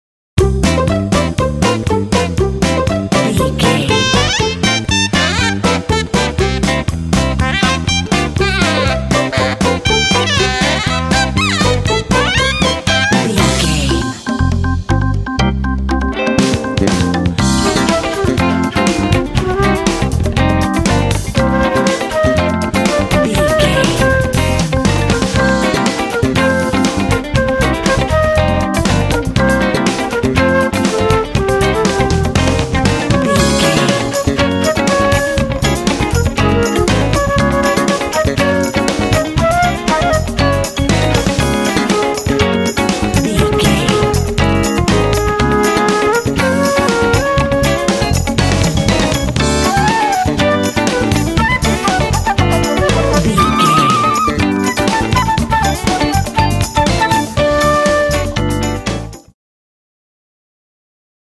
Uplifting
Aeolian/Minor
funky
energetic
cheerful/happy
strings
saxophone
drums
electric guitar
electric organ
flute
bass guitar
Funk
soul
blues